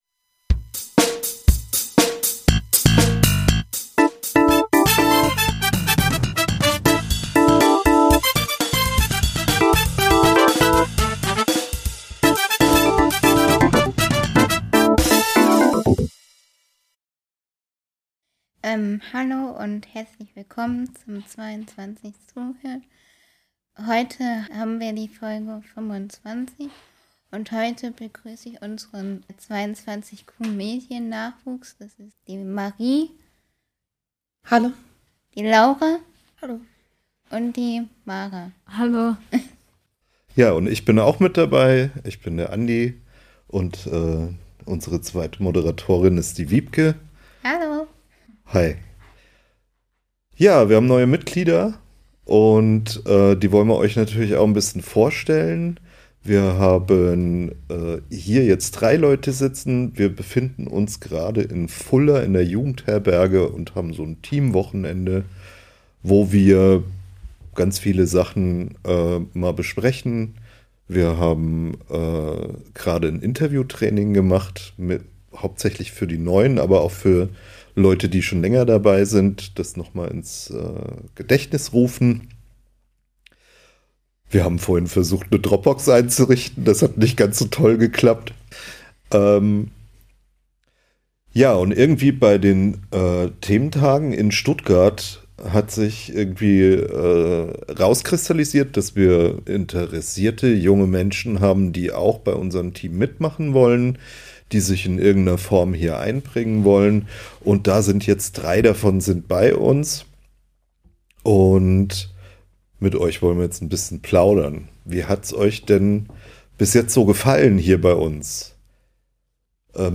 Ein Gespräch mit den neuen Projektmitgliedern